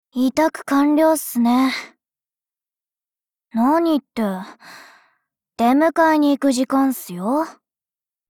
贡献 ） 协议：Copyright，人物： 碧蓝航线:羽黑语音 您不可以覆盖此文件。